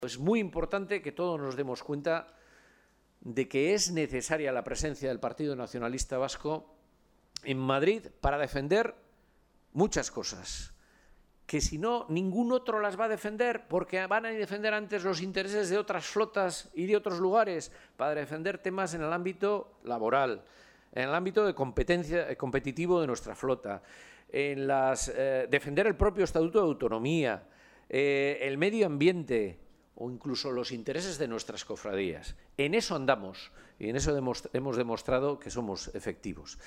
El candidato jeltzale a la reelección por Bizkaia en el Congreso ha estado esta mañana en Bermeo para reivindicar la relevancia del sector marítimo y pesquero “tanto para la economía y como para la sociedad vasca”, y ha revalidado la apuesta de EAJ-PNV con el sector.